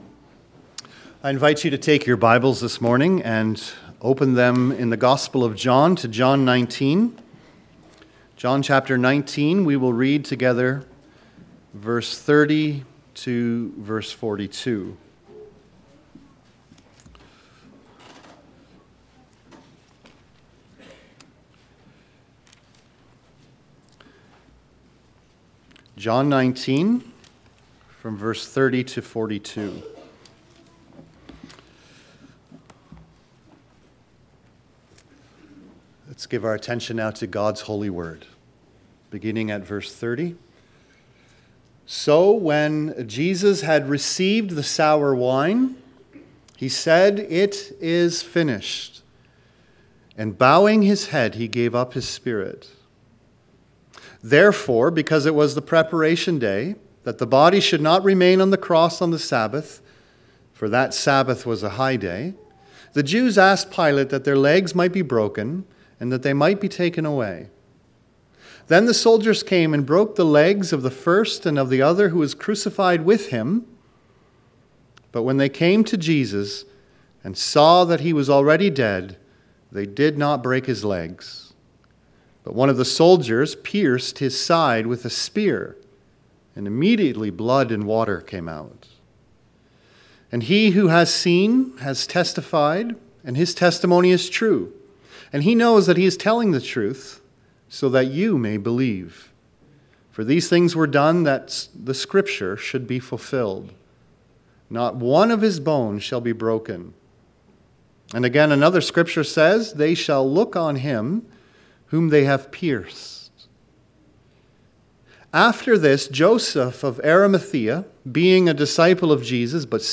John 9:30-42 Service Type: Sunday Morning « On this rock I will build My church A before and after picture